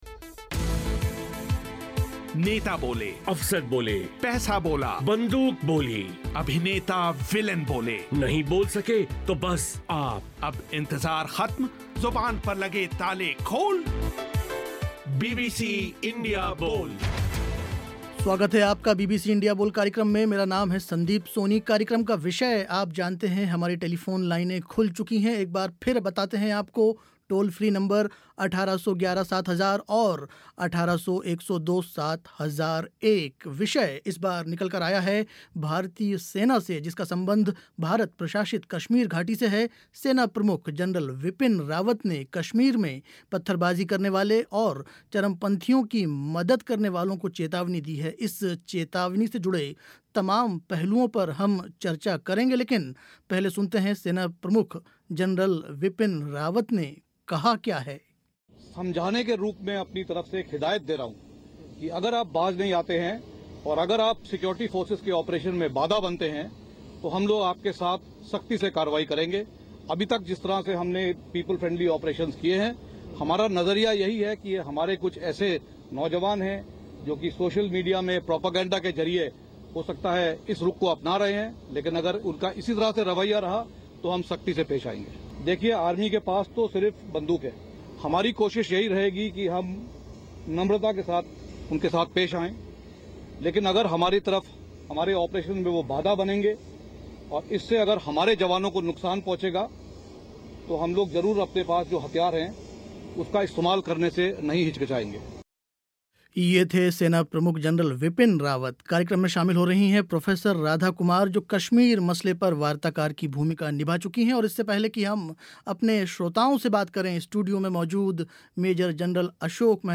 कश्मीरियों के दिलो-दिमाग को जीतने के लिए क्या किया जाना चाहिए. बीबीसी इंडिया बोल में आज चर्चा हुई इसी विषय पर.